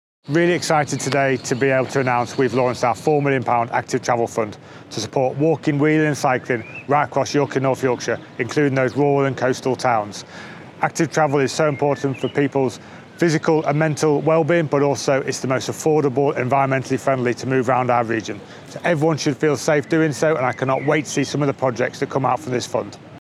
Mayor David Skaith on the Active Travel Fund